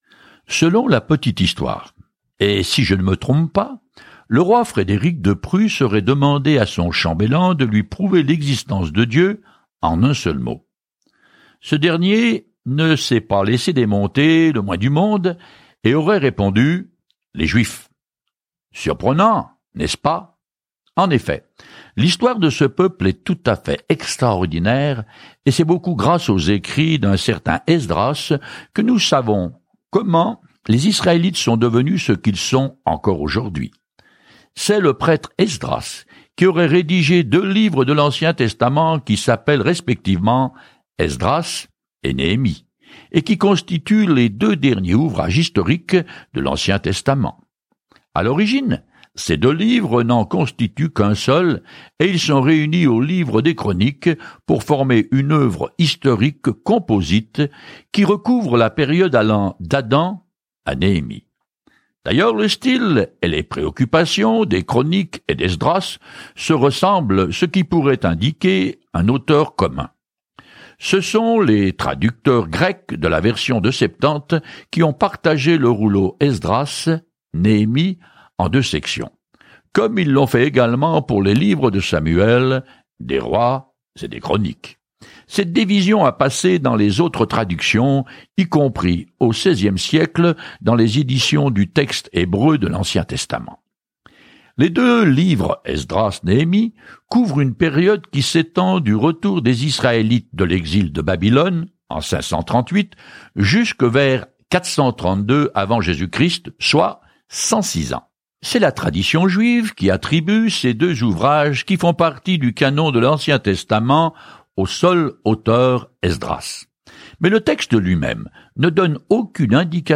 Écritures Esdras 1:1-2 Commencer ce plan Jour 2 À propos de ce plan Le peuple d’Israël, revenu de captivité, reconstruit le temple de Jérusalem, et un scribe nommé Esdras lui enseigne comment obéir à nouveau aux lois de Dieu. Voyagez quotidiennement à travers Ezra en écoutant l’étude audio et en lisant certains versets de la parole de Dieu.